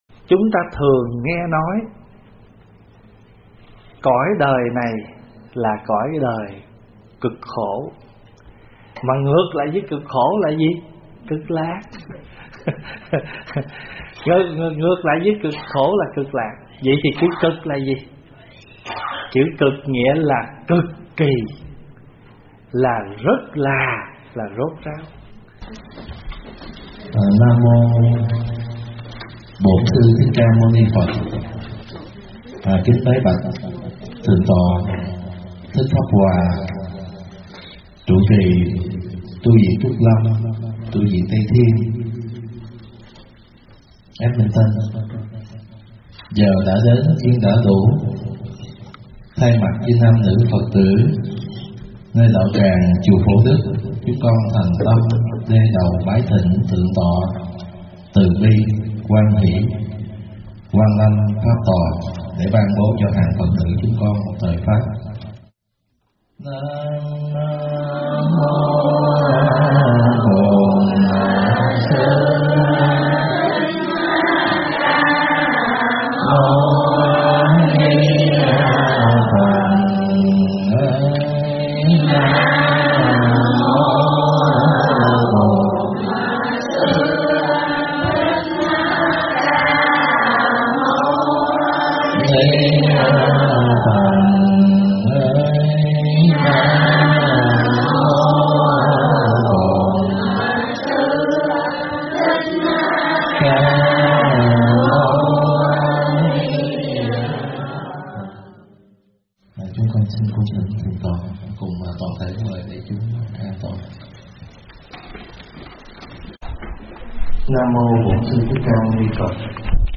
Mp3 thuyết pháp Cực Khổ - Cực Lạc - ĐĐ.
tại chùa Phổ Đức (Calgary)